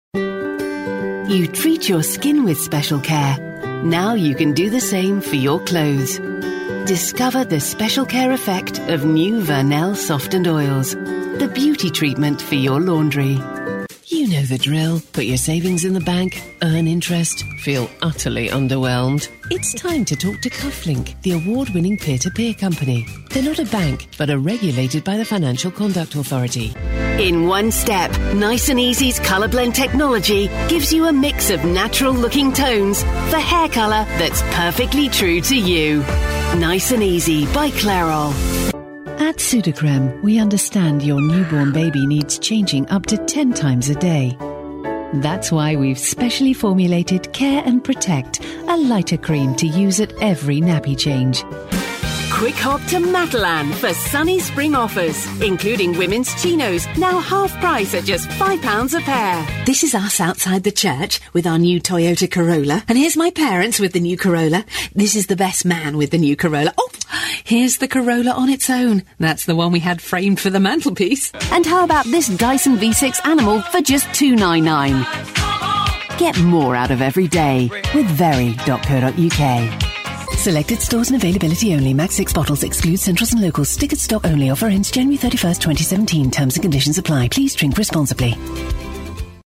UK British Female Voiceover
COMMERCIAL